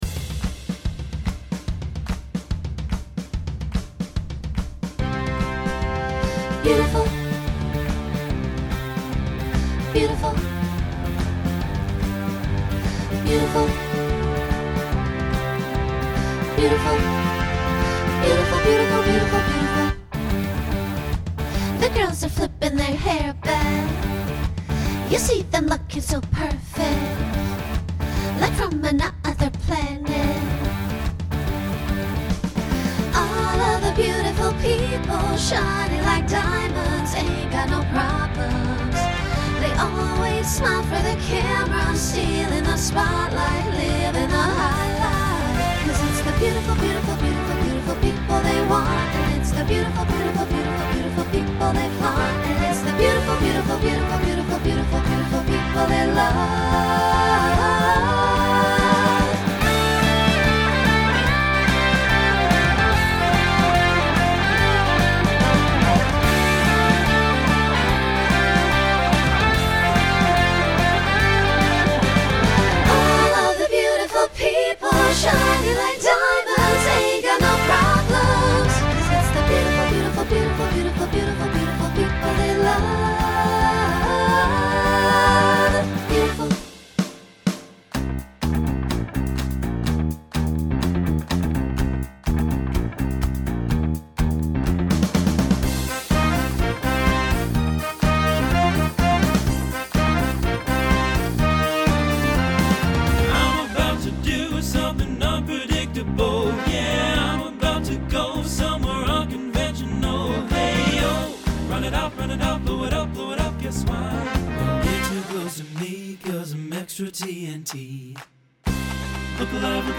Genre Pop/Dance , Rock Instrumental combo
Transition Voicing Mixed